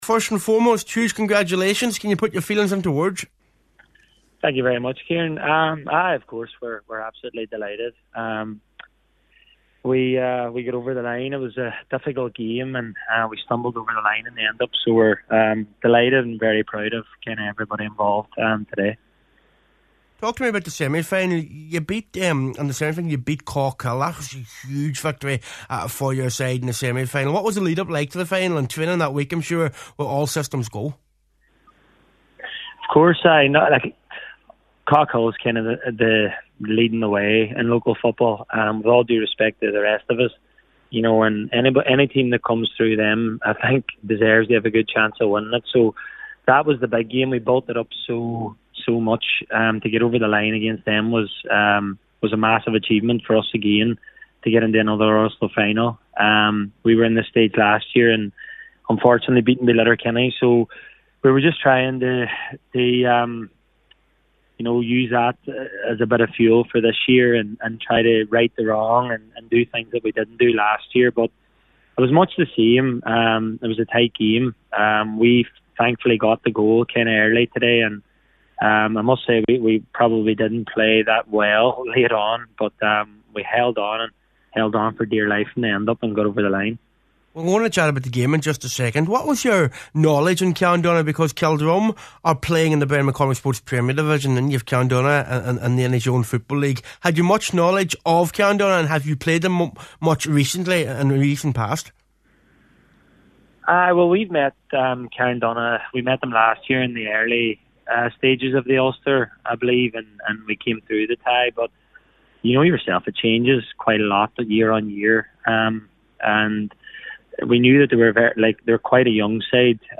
took time out of the celebrations to speak to Highland’s